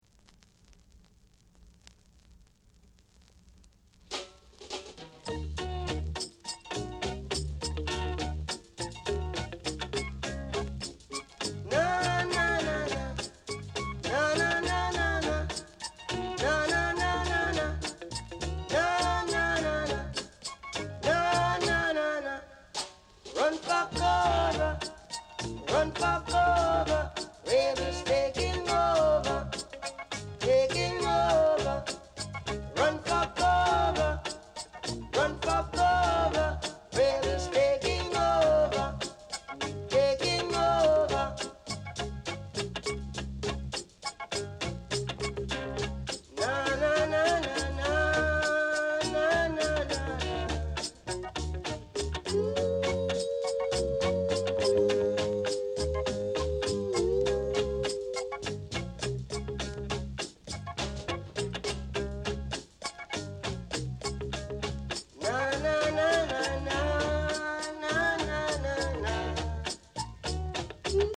Reggae Vocal Group
Rare UK press! great reggae vocal w-sider!